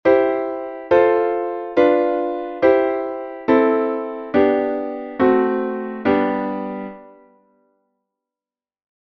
C-G Modulation
Von C-Dur zu G-Dur
Die Modulation endet wieder klassisch mit T – D – T der neuen Tonart.
C-G-Modulation.mp3